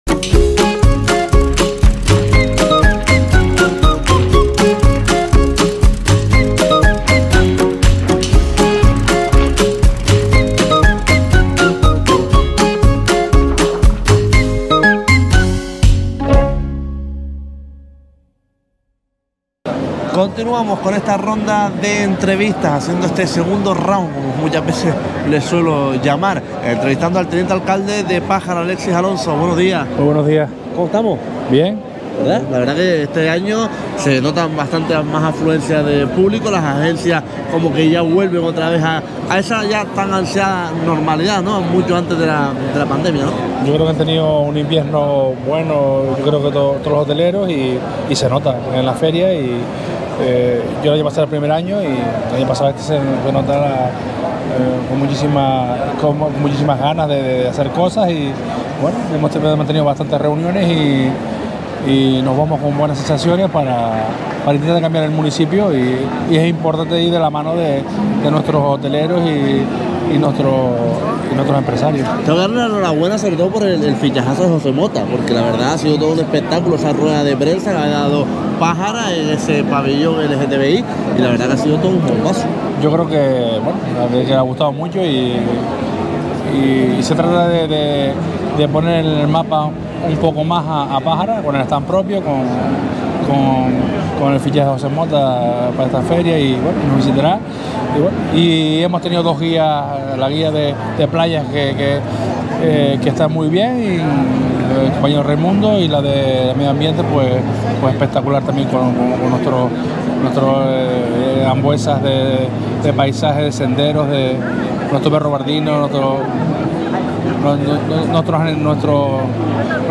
Fitur 2024: Entrevista a Alexis Alonso
Entrevistamos al Teniente Alcalde de Pájara Fitur 2024.